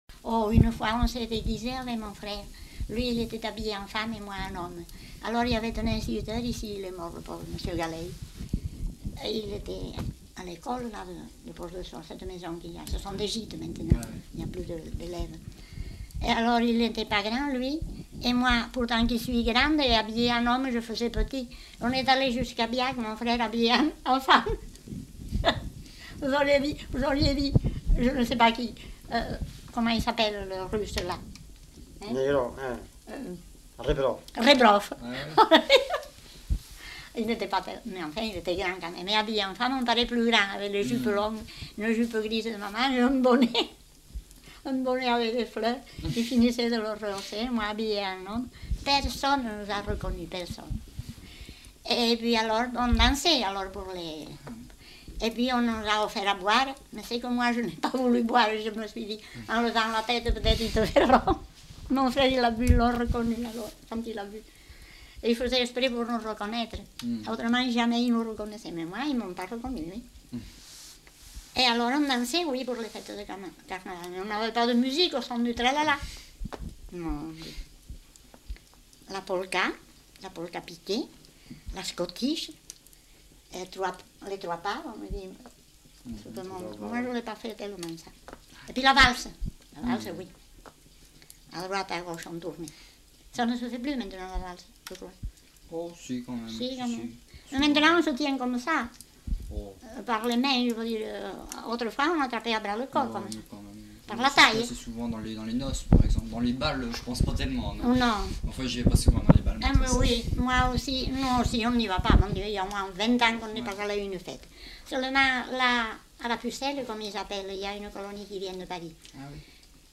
Lieu : Pause-de-Saut (lieu-dit)
Genre : témoignage thématique